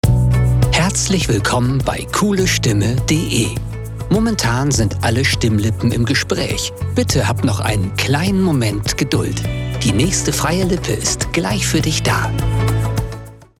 Commerciale, Cool, Mature, Amicale, Corporative
Téléphonie